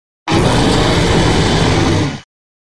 Grito de Zekrom.ogg
Grito_de_Zekrom.ogg